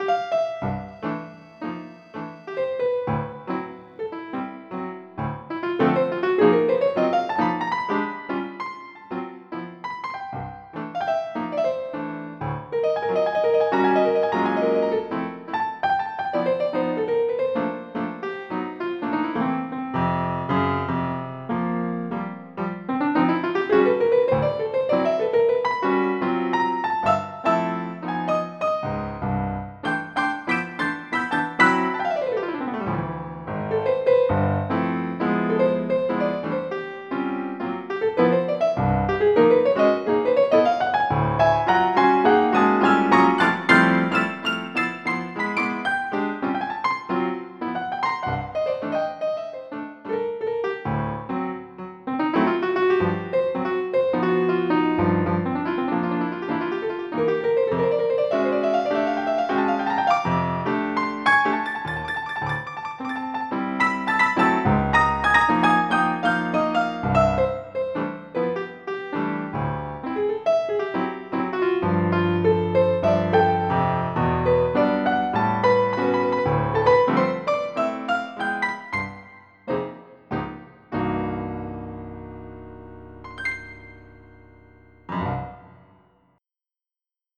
MIDI Music File
Type General MIDI
S2JAZZ.mp3